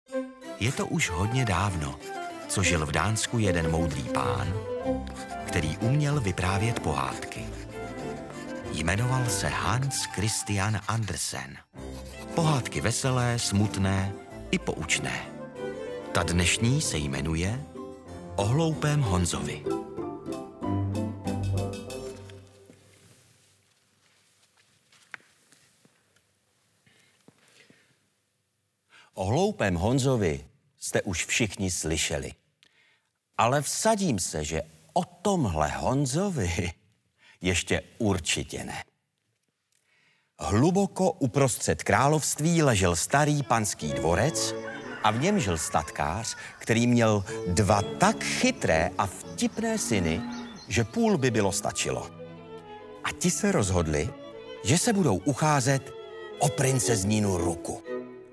Délka: 2 h 15 min Interpret: Otakar Brousek ml.
Audioknihy